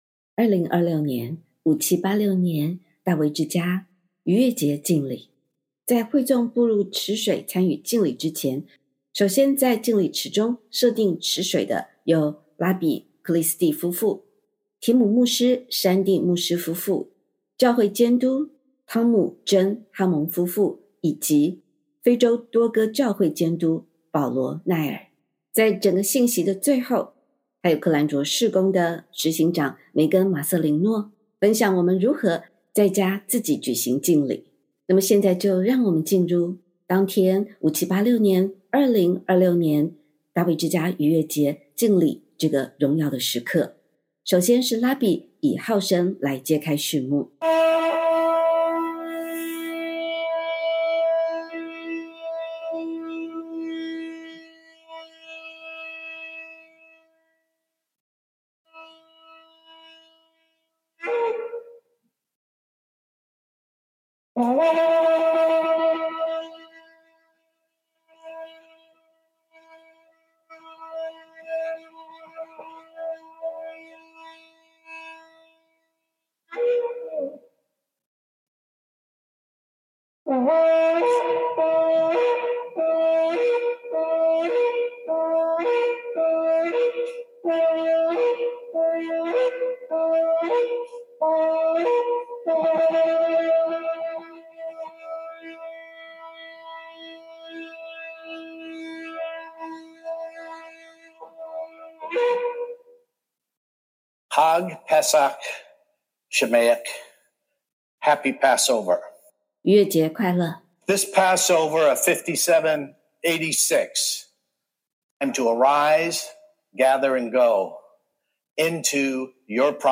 号角揭幕： 迈向应许之地的季节 拉比以号角声揭开本次逾越节敬礼序幕。